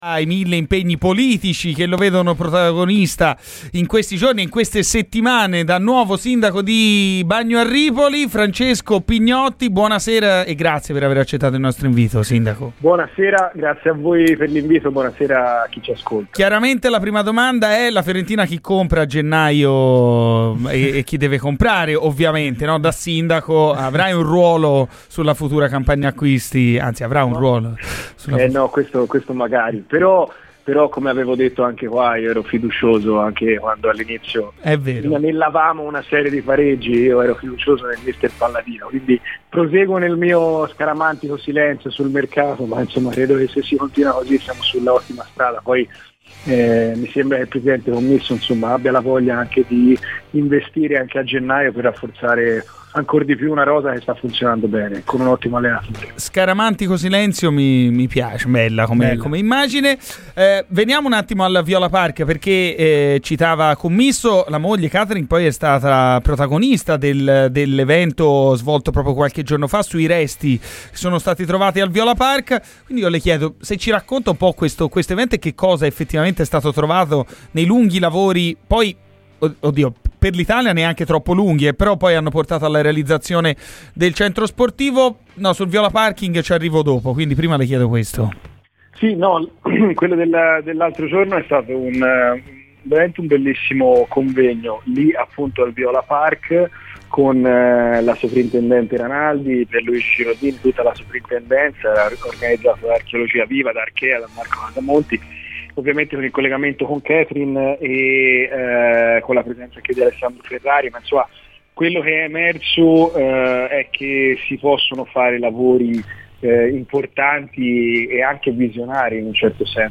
Francesco Pignotti, sindaco di Bagno a Ripoli, durante "Garrisca al Vento" su Radio FirenzeViola ha parlato della situazione Viola Parking (qui i dettagli).